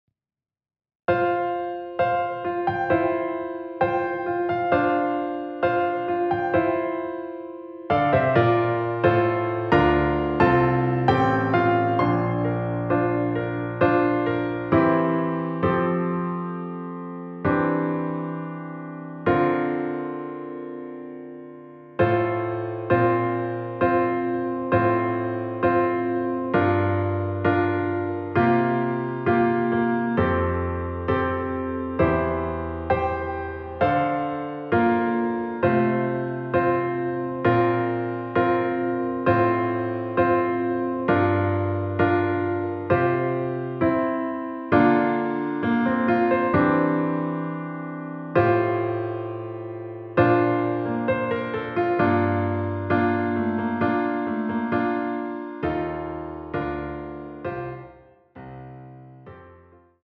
반주가 피아노 하나만으로 되어 있습니다.(미리듣기 확인)
원키에서(+4)올린 피아노 버전 MR입니다.
앞부분30초, 뒷부분30초씩 편집해서 올려 드리고 있습니다.
중간에 음이 끈어지고 다시 나오는 이유는